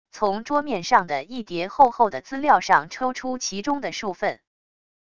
从桌面上的一叠厚厚的资料上抽出其中的数份wav音频